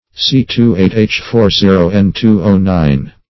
antimycin \an`ti*my"cin\ ([a^]n`t[i^]*m[imac]"s[i^]n), n.